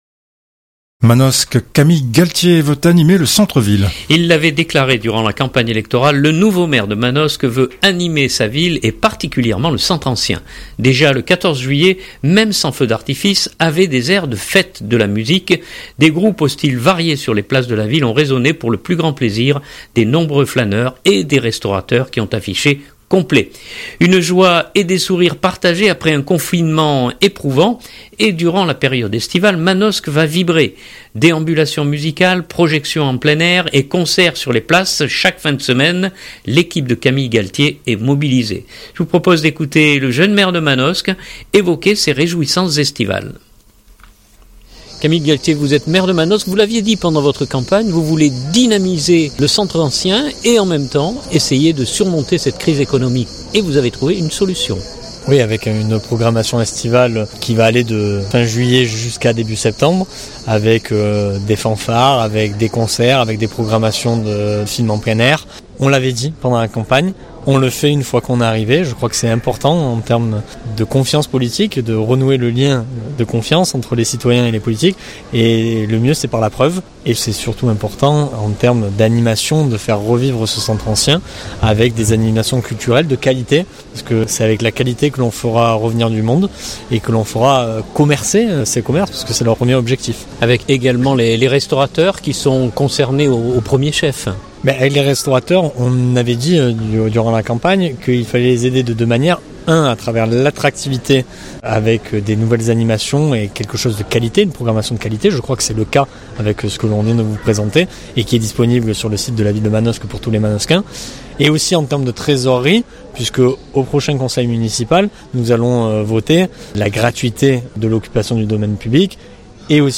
Et durant la période estivale, Manosque va vibrer : déambulations musicales, projections en plein air et concerts sur les places chaque fin de semaine, l’équipe de Camille Galtier est mobilisée. Je vous propose d’écouter le jeune maire de Manosque évoquer ces réjouissances estivales.